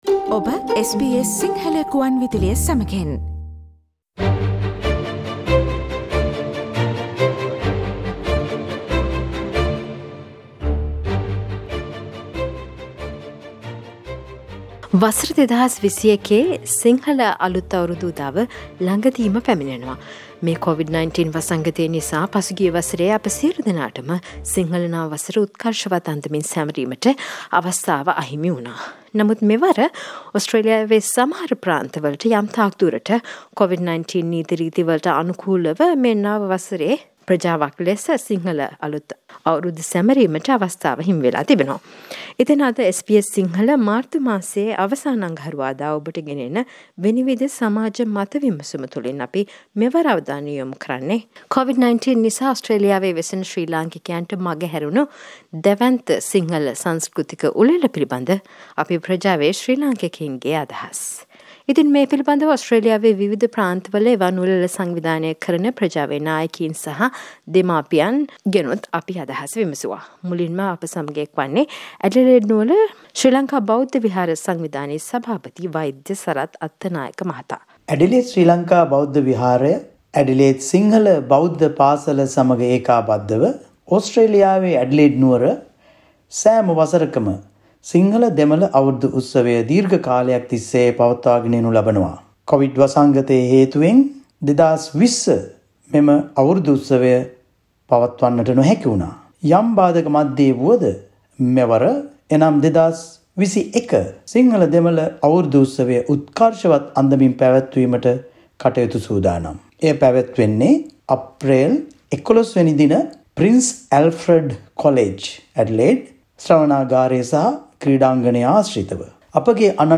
You can listen to members from Sri Lankan community about how they feel about celebrating the Sinhala New Year in 2021.
SBS Sinhala Vinivida monthly Panel Discussion focused on how Sri Lankans in Australia celebrate Sinhala New Year amid Corona restrictions.